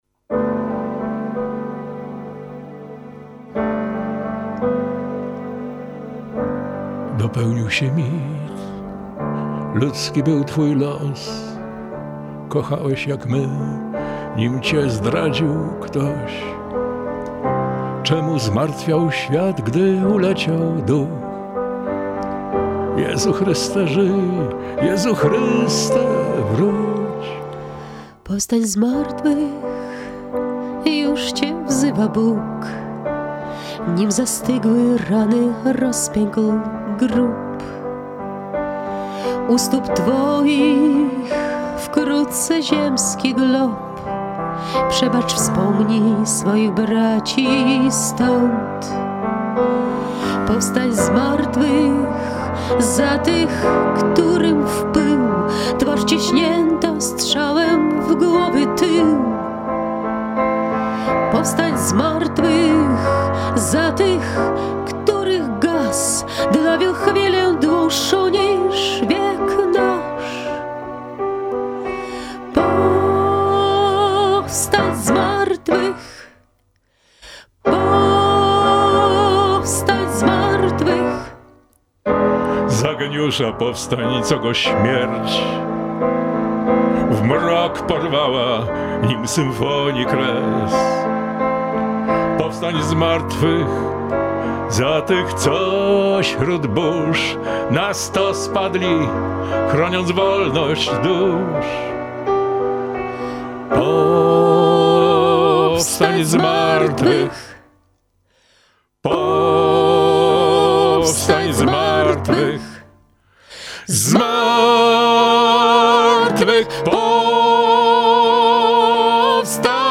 poeta i piosenkarz